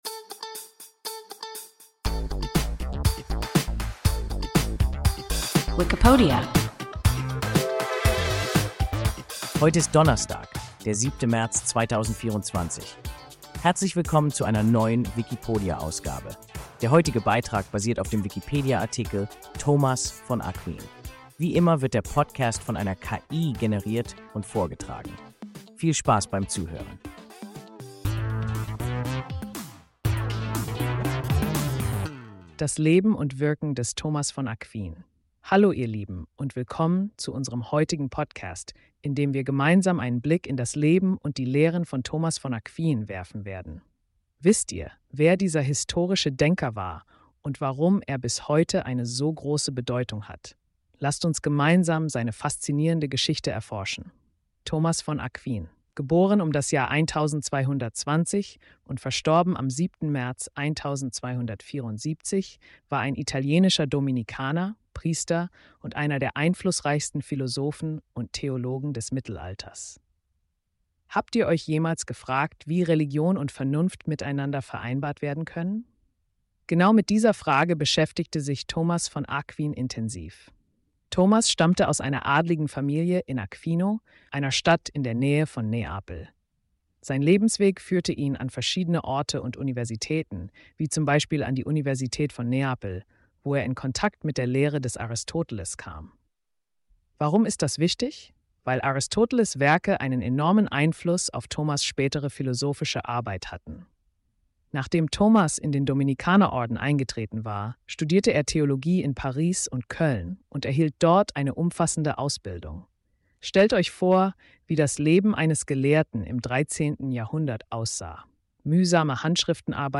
Thomas von Aquin – WIKIPODIA – ein KI Podcast